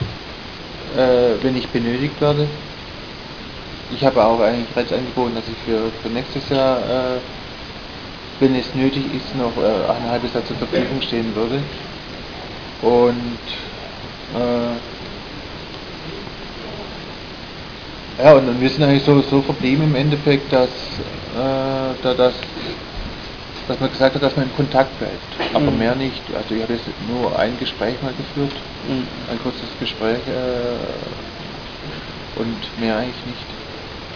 ブッフバルト選手退団会見！
マークはギドの肉声が聞けます！